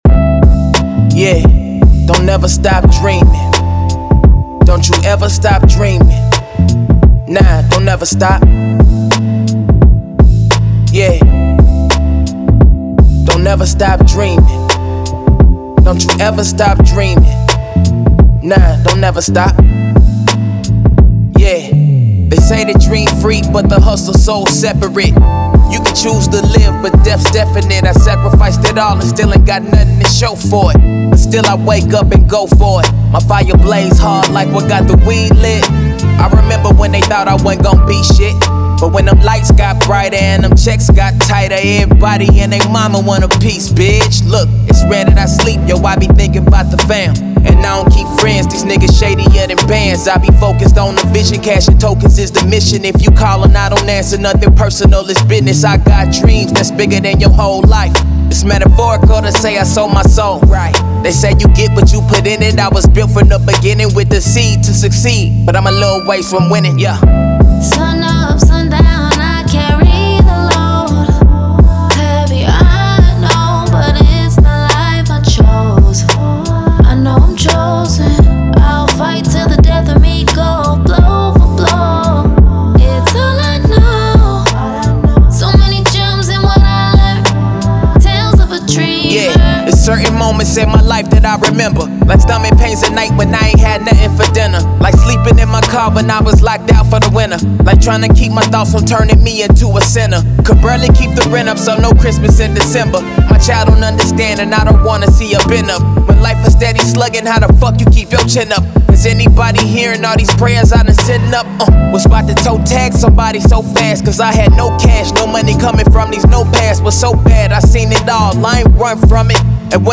Hip Hop, R&B
A Minor